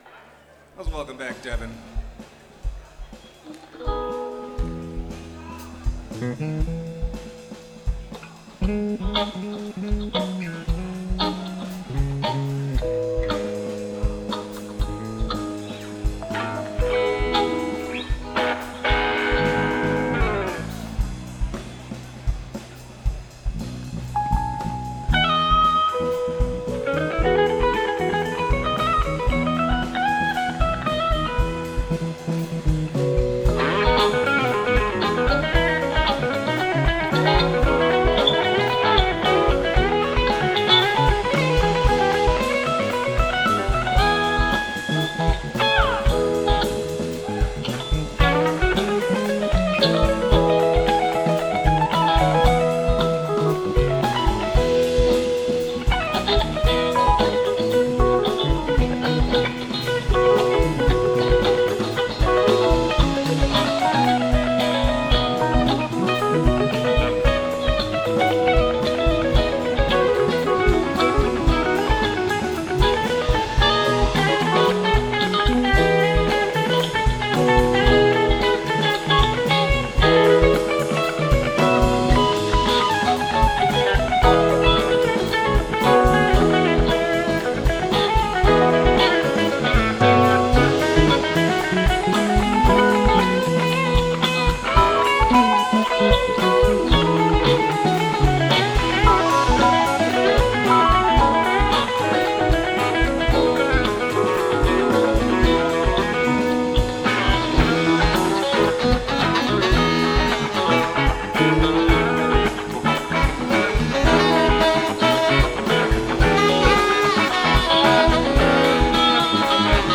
cover band from Madison, WI.